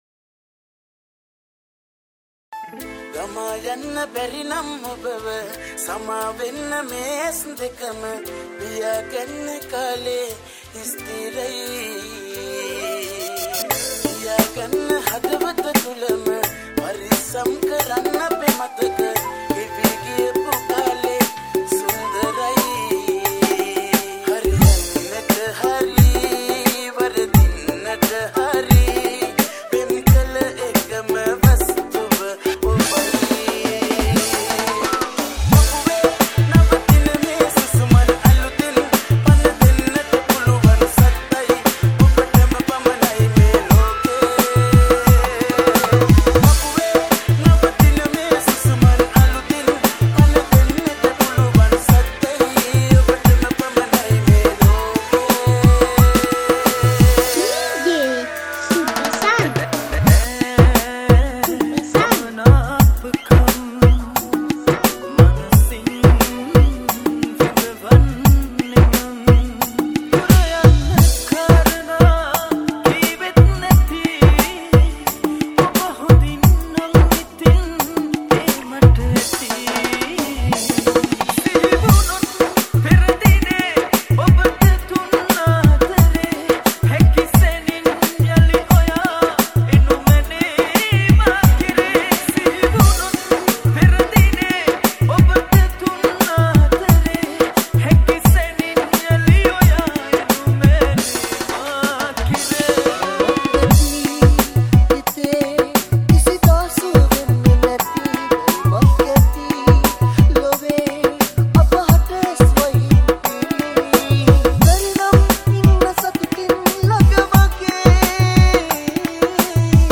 Category: Dj Remix